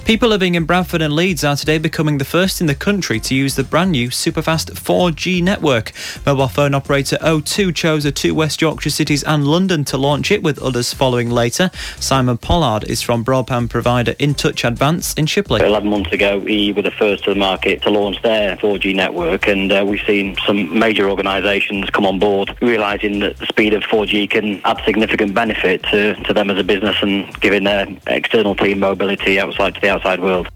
Intouch Advance Pulse Radio Interview
Intouch-Radio-Interview.mp3